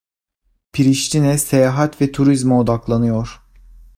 Pronounced as (IPA)
/se.ja.ˈhat/